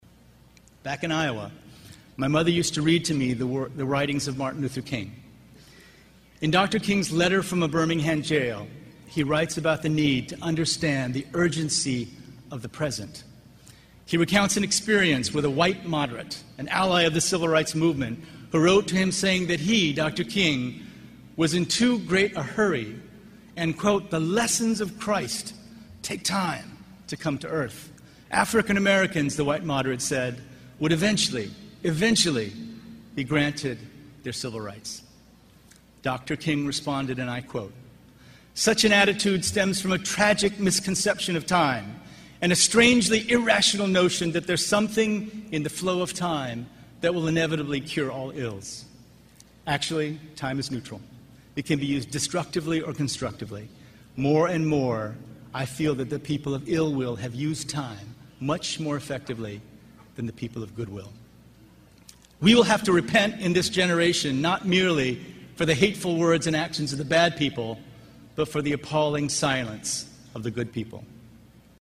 公众人物毕业演讲 第70期:金墉美国东北大学(9) 听力文件下载—在线英语听力室